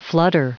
Prononciation du mot flutter en anglais (fichier audio)
Prononciation du mot : flutter